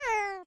animalia_cat_hurt.ogg